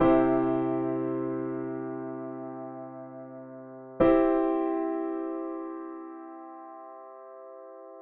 An example of a four-part chord with open voicing.
In the example above, it is the root.